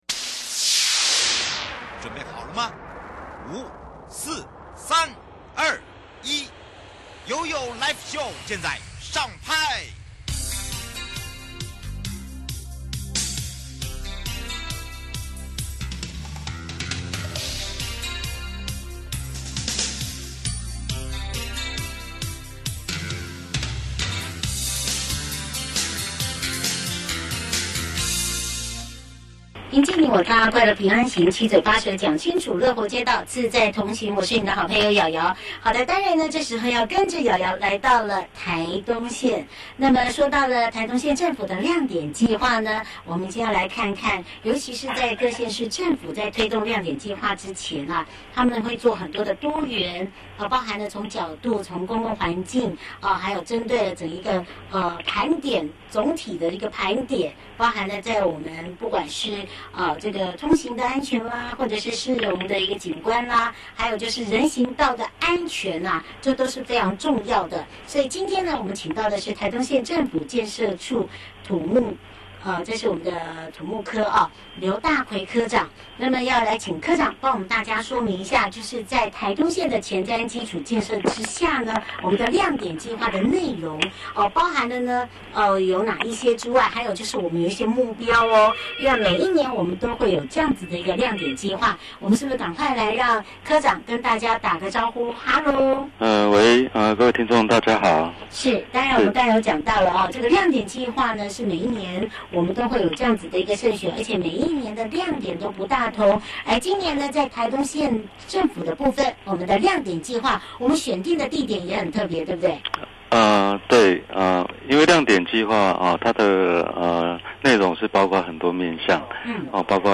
受訪者： 營建你我他 快樂平安行-台東縣政府-亮點計畫 (上集) 台東縣政府選點的位置跟最想改變的目標(亮點)